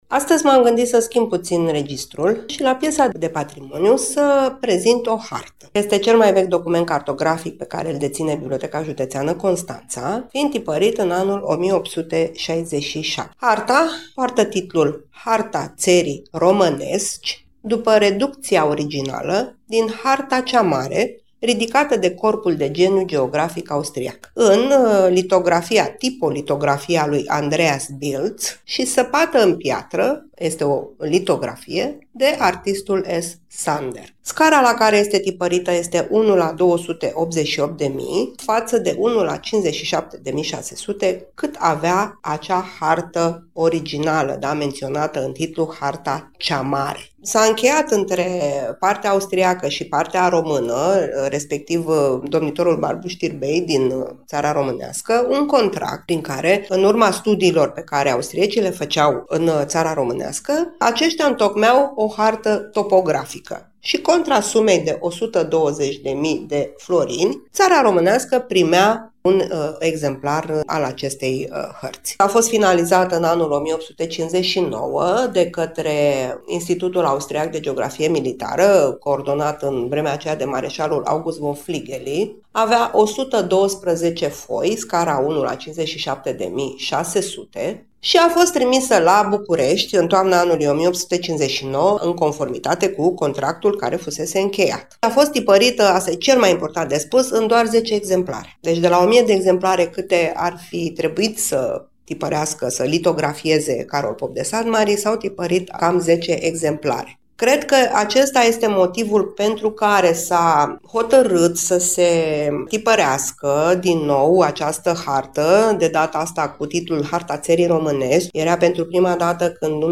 Bibliotecarul